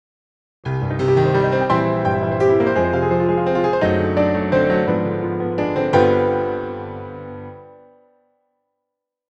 はなんかドラマチックだよねっ！
ロマン派ドラマチック感情的物語性に富んでる。